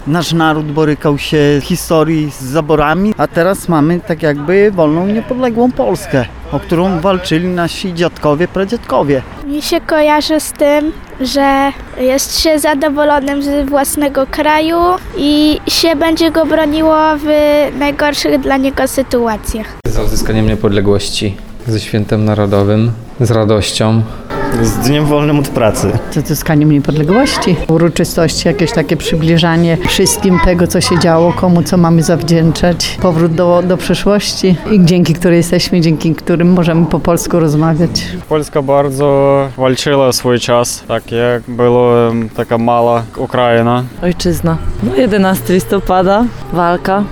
W Suwałkach, tak jak w całym kraju, obchodzimy dziś (11.11) Święto Niepodległości. Z tej okazji, zapytaliśmy mieszkańców, z czym kojarzy się im patriotyzm i niepodległość.